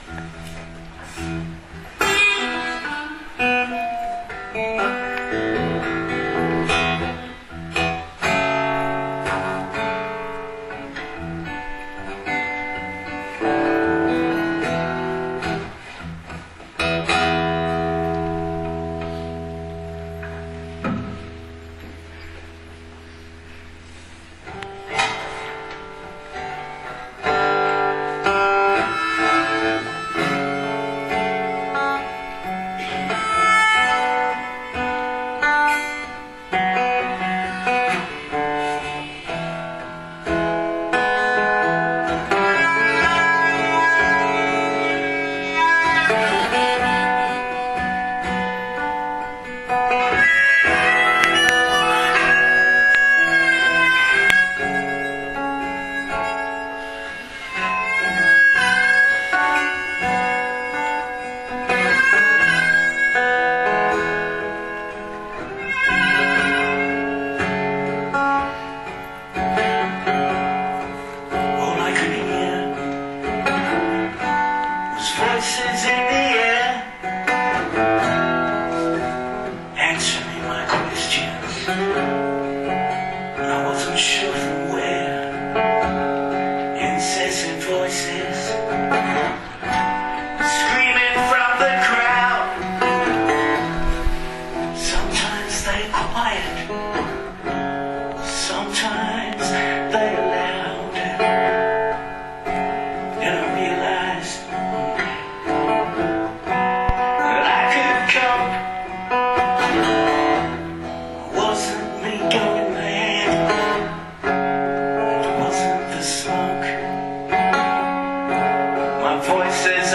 Vom "The World Hearing Voices Congress", den ich im November 2013
Words & music by Louisa Talbot of Hamilton NZ.